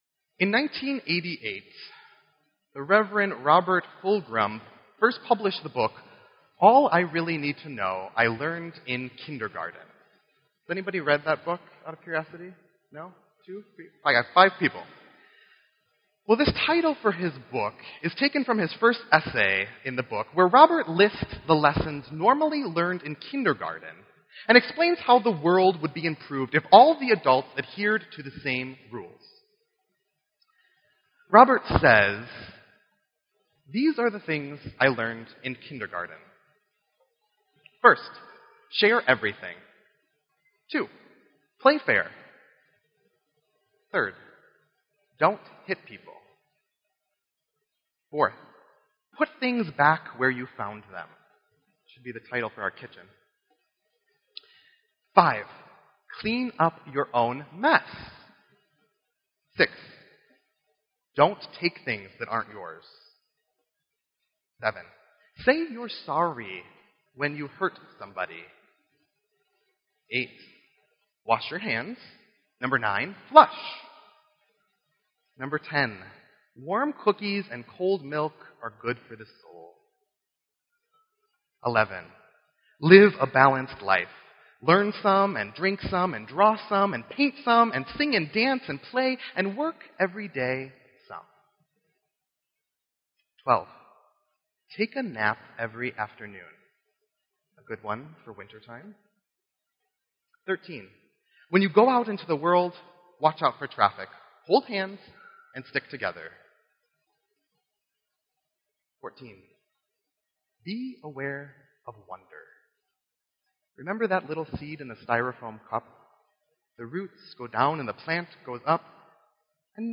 Wicker Park Lutheran Church Preaching Fall 2015